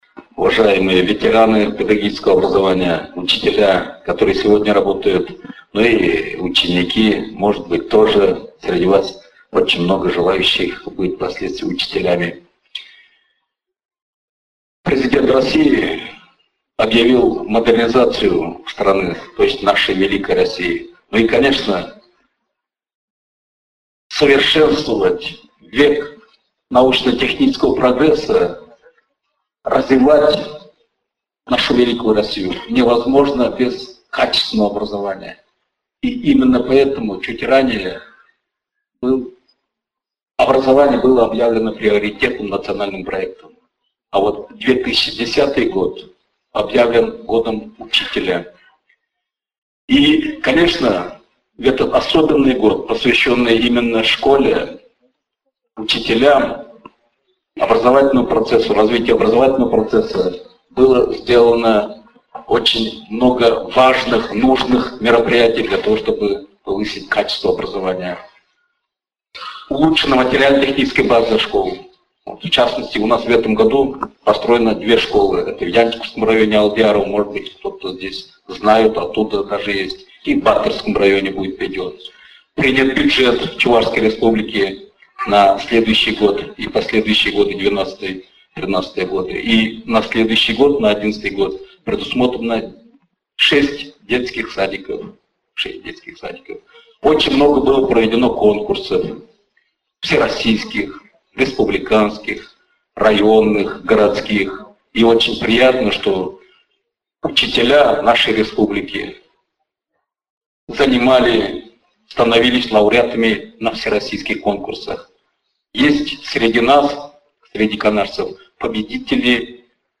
Церемония закрытия Года учителя состоялась 23 декабря 2010 года во Дворце культуры города Канаша.
Право поздравить первыми учителей за их самоотверженный труд на сцену были приглашены заместитель Председателя Государственного Совета Чувашской Республики Генрих Геннадьевич Васильев и глава города Канаша Владимир Николаевич Вишневский.